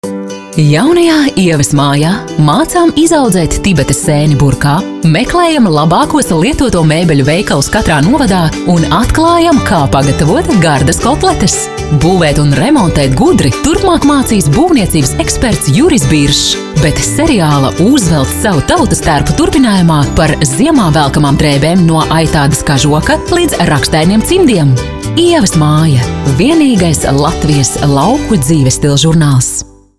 Native speaker Female 20-30 lat
Nagranie lektorskie